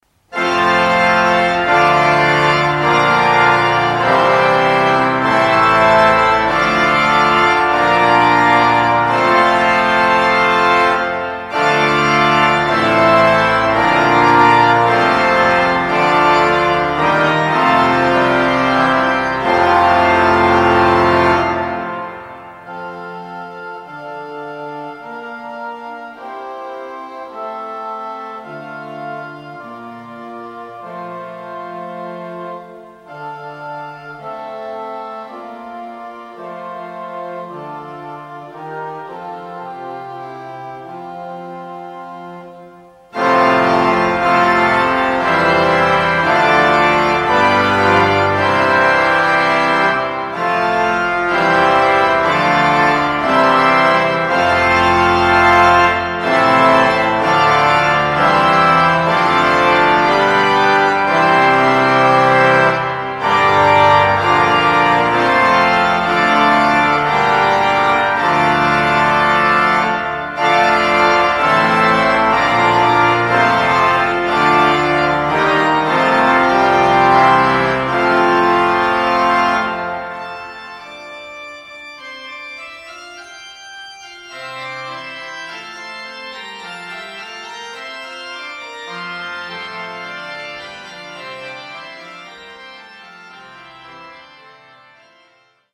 Aedian Skinner-Orgel der Riverside Church, New York